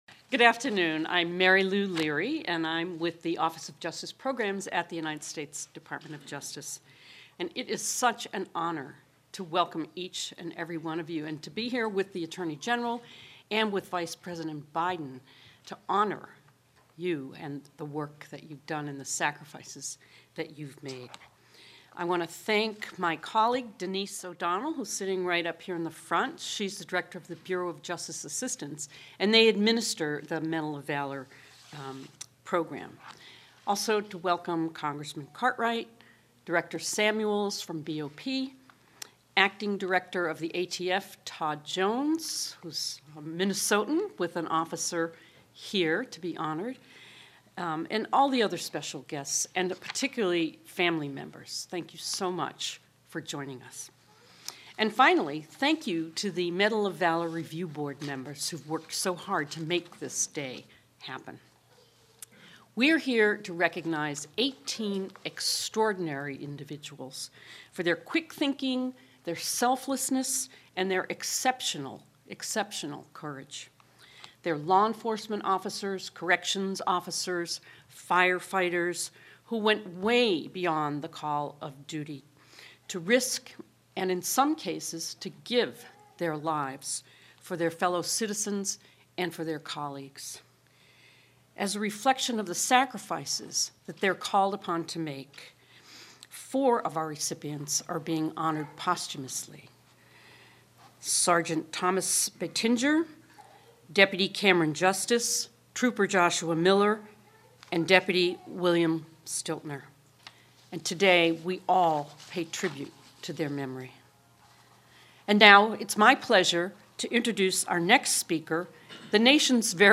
U.S. Vice President Joseph Biden hosts the Medal of Valor ceremony with Attorney General Eric Holder at the White House
Deputy Attorney General Mary Lou Leary convenes the ceremony and reads the official citations.